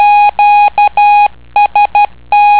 Jeśli posiadasz kartę dźwiękową możesz odsłuchać niektóre kody klikając myszką na kodzie z odnośnikiem)